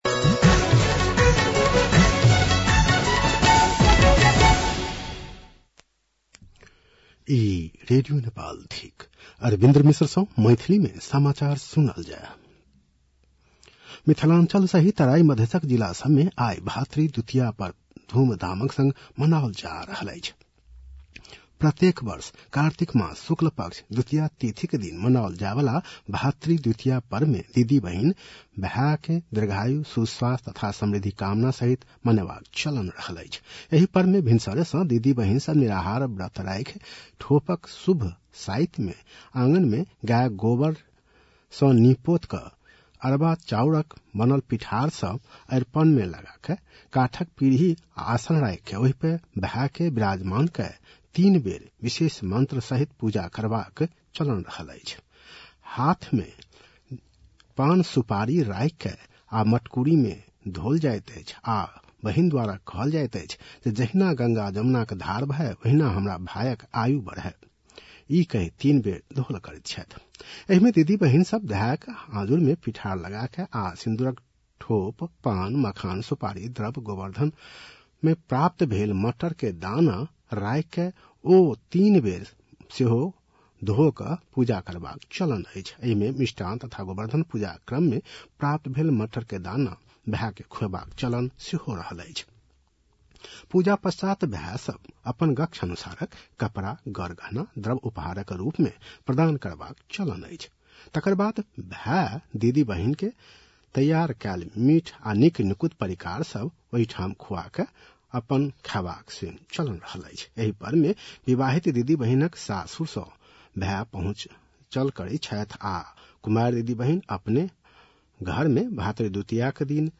मैथिली भाषामा समाचार : ६ कार्तिक , २०८२